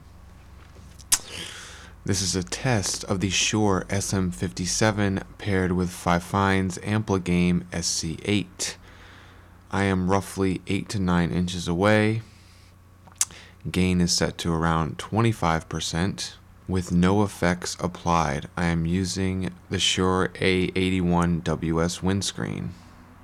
The SC8 has this subtle crackly character and sounds a bit distorted vs. the very clean signal coming out of the Volt 2.
Interestingly enough, recordings made with the SM57 through the Ampligame inside Audacity may sound slightly cleaner or fuller compared to those captured in FL Studio.
Fifine_Ampligame_SM57_Audacity_Trimmed.wav